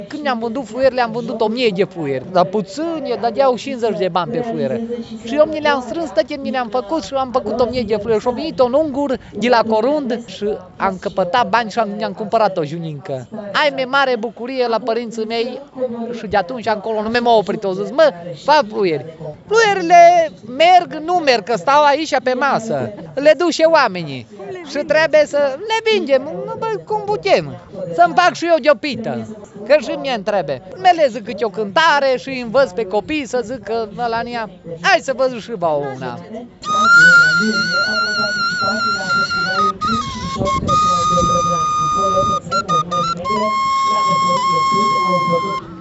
Festivalul Văii Gurghiului a devenit un reper obligatoriu pentru iubitorii de folclor care știu că la sfârșit de august, la Fâncel, găsesc tot ce le aduce aminte de copilărie.